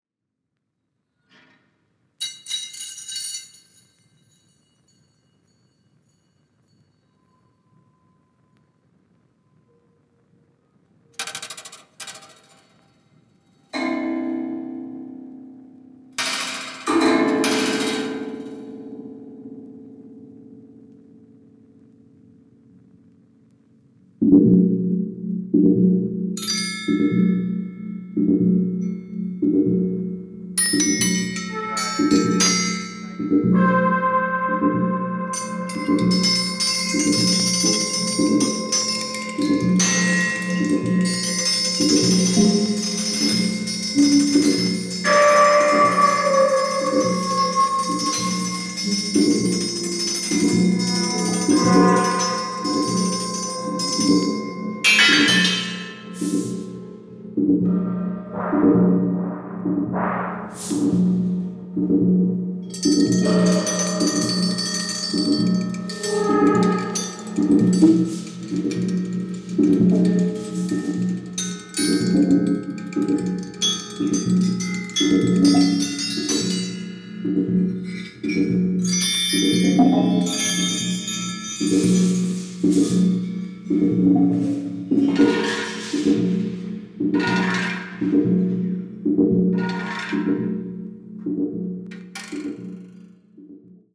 静寂、瞬発力、人間の感情を上手に表現されてます。